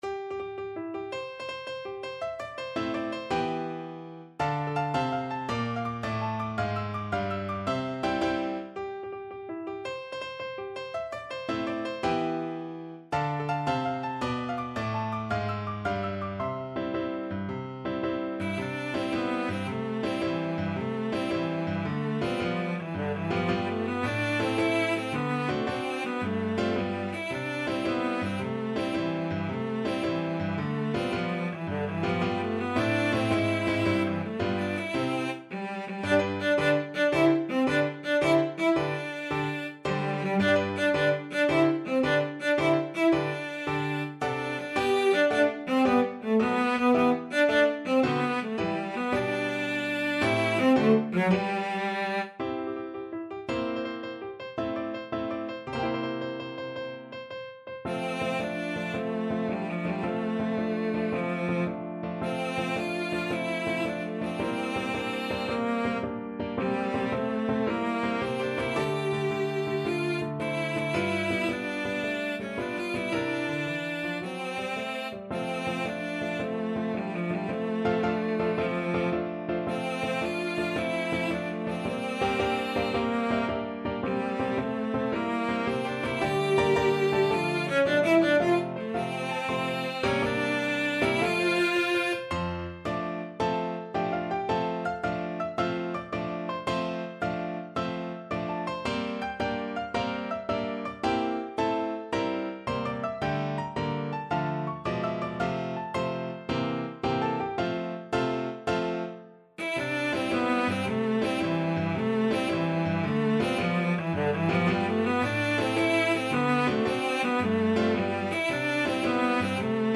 Cello version
6/8 (View more 6/8 Music)
March .=c.110
Classical (View more Classical Cello Music)